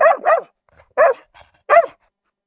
Dog Barking
A medium-sized dog barking alertly with pauses between each sharp, clear bark
dog-barking.mp3